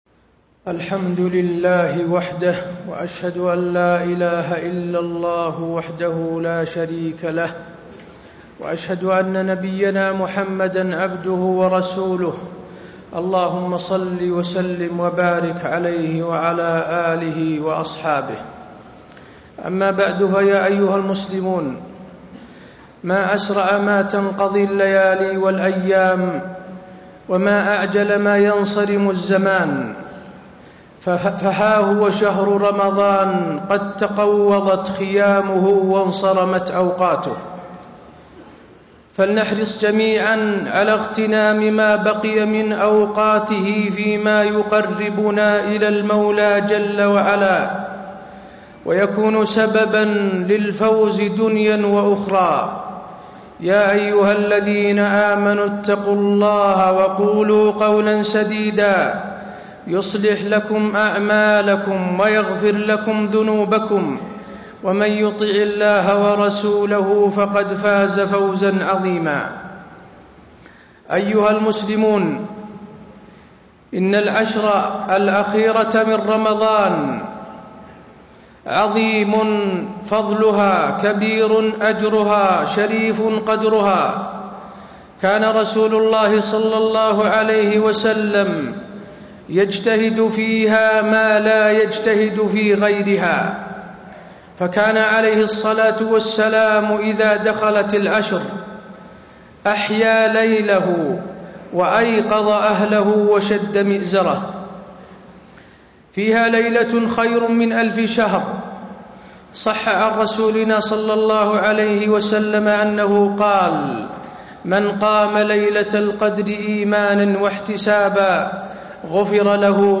تاريخ النشر ٢٢ رمضان ١٤٣٣ هـ المكان: المسجد النبوي الشيخ: فضيلة الشيخ د. حسين بن عبدالعزيز آل الشيخ فضيلة الشيخ د. حسين بن عبدالعزيز آل الشيخ الإعتكاف وآدابه